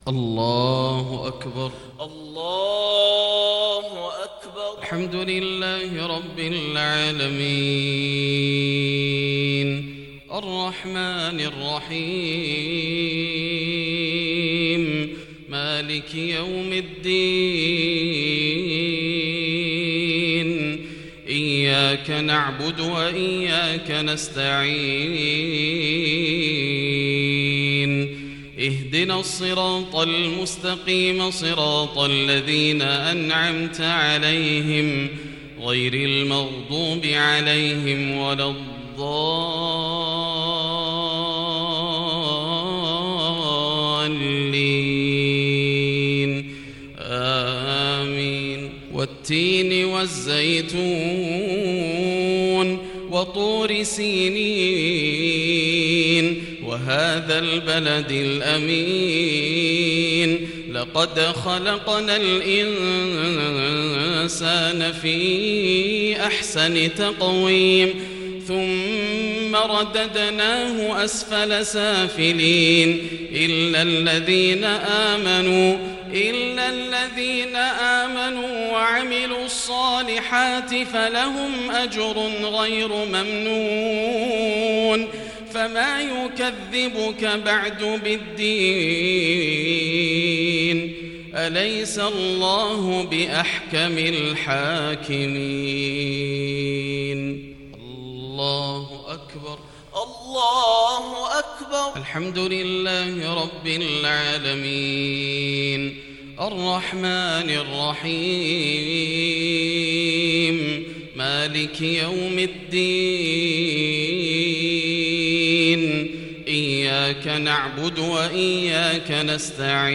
صلاة المغرب للشيخ ياسر الدوسري 20 شعبان 1441 هـ
تِلَاوَات الْحَرَمَيْن .